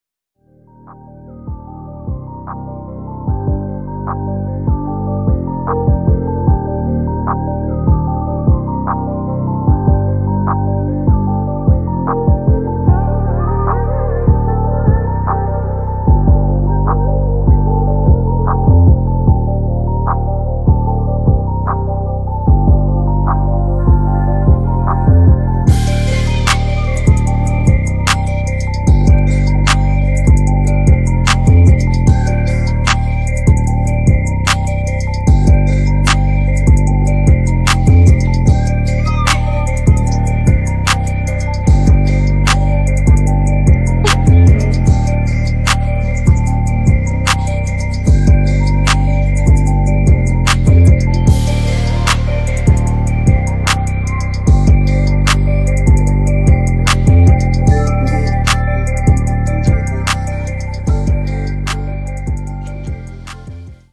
спокойные без слов атмосферные бит инструментал качающие